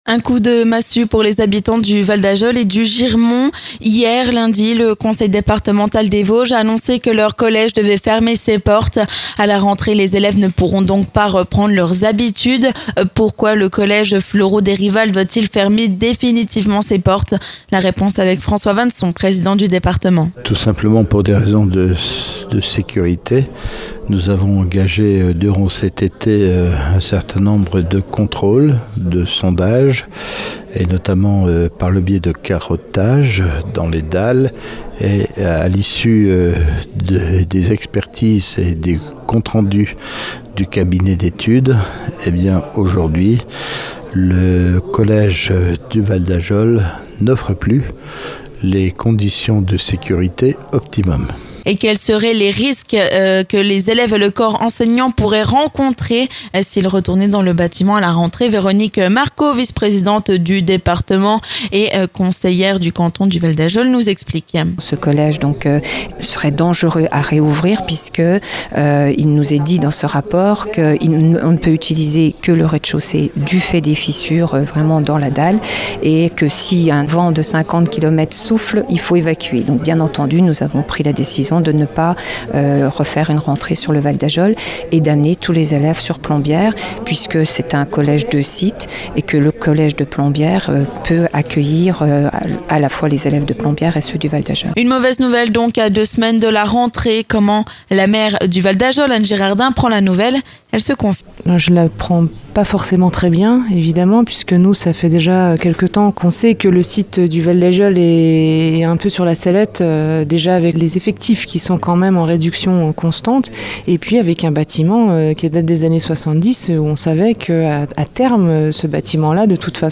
Ecoutez François Vannson, président du Conseil départemental des Vosges ainsi que Véronique Marcot, vice-présidente et conseillère départementale sur le canton du Val-d'Ajol. Anne Girardin et Patrick Vincent, respectivement maire du Val-d'Ajol et du Girmont nous livrent également leur ressenti.